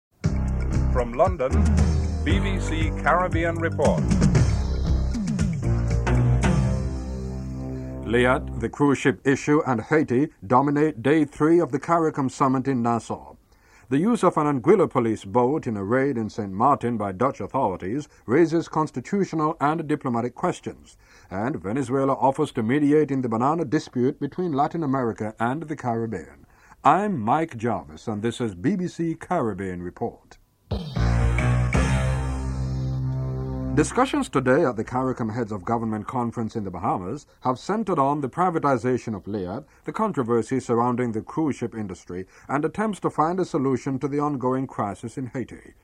1. Headlines (00:00-00:40)
2. Discussions at the CARICOM Heads of Government Conference in the Bahamas centre on Trinidad and Tobago’s Prime Minister, Patrick Manning announcement in favour of a Caribbean political union, the privatization of LIAT, the controversy surrounding the cruise ship passenger tax and attempts to find a solution to the crisis in Haiti. Interview with Rueben Meade, Chief Minister of Anguilla and Erskvine Sandiford, Prime Minister of Barbados.